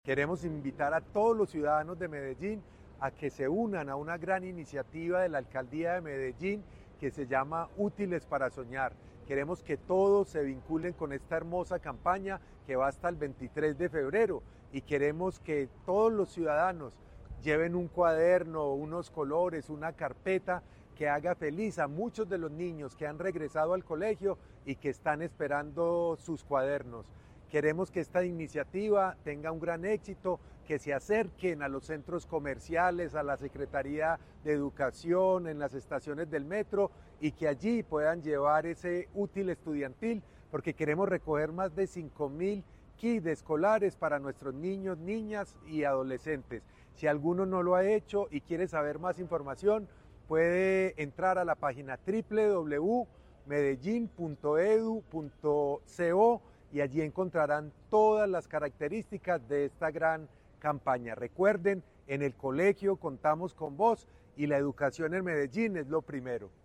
Palabras de Luis Guillermo Patiño Aristizábal, secretario de Educación